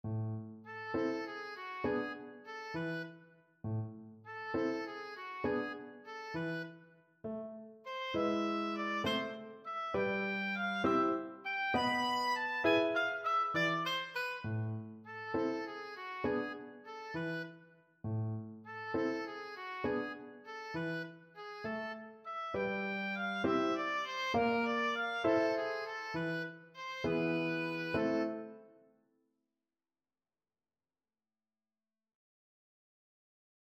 A minor (Sounding Pitch) (View more A minor Music for Oboe )
6/8 (View more 6/8 Music)
Moderato
Classical (View more Classical Oboe Music)